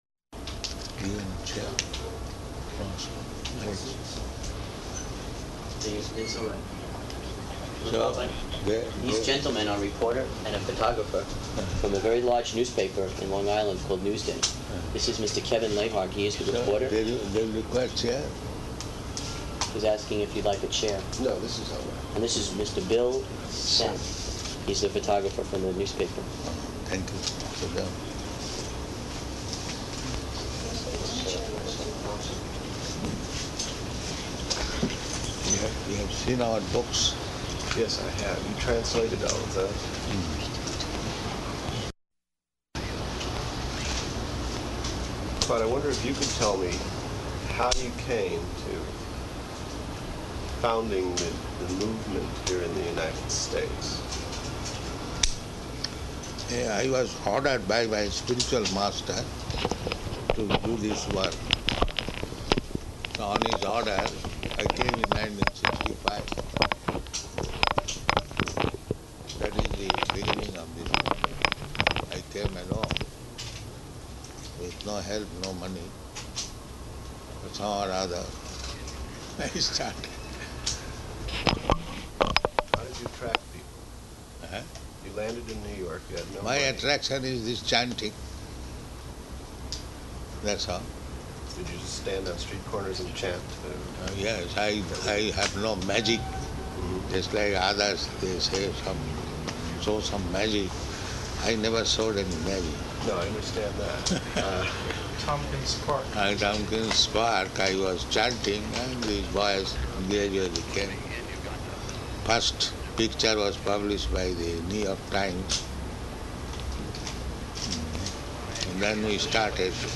Interview with Newsday Newspaper
Interview with Newsday Newspaper --:-- --:-- Type: Lectures and Addresses Dated: July 14th 1976 Location: New York Audio file: 760714I2.NY.mp3 Prabhupāda: Give him chair.